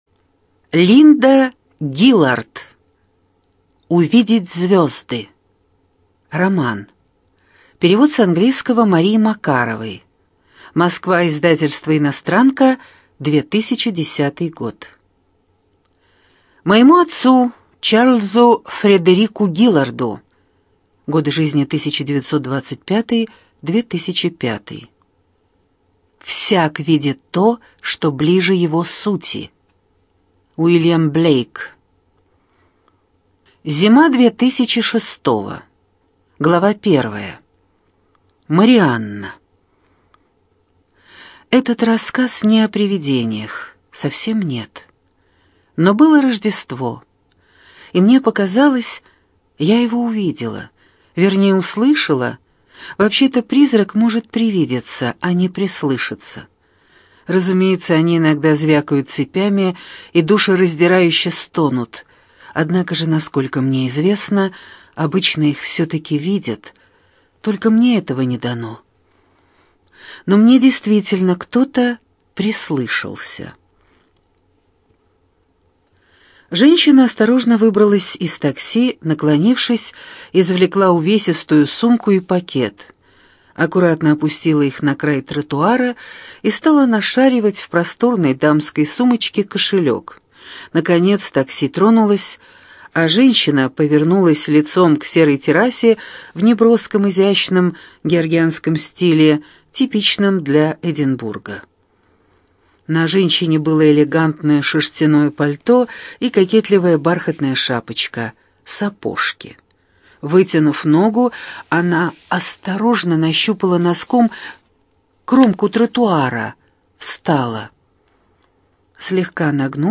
ЖанрЛюбовная проза, Современная проза
Студия звукозаписиЛогосвос